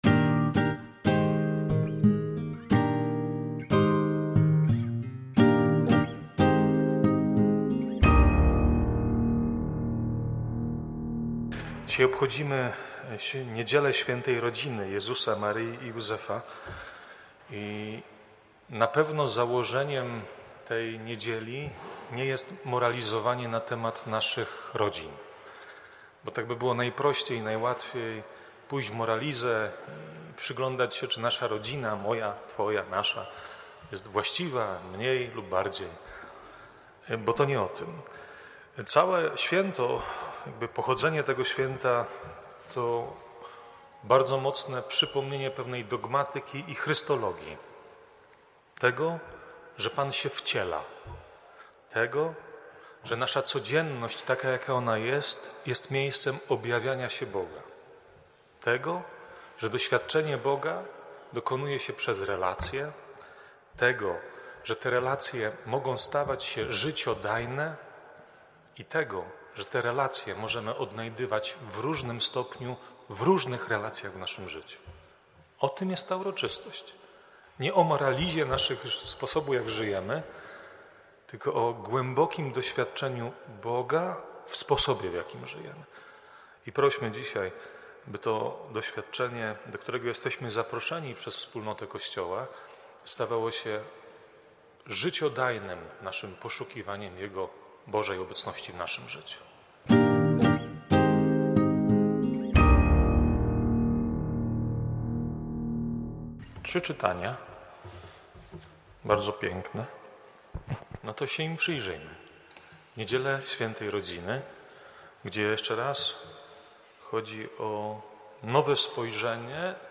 wprowadzenie do Liturgii, oraz homilia: